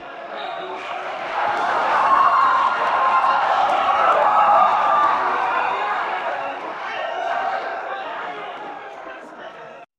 Prompt : Crowd in stadium cheering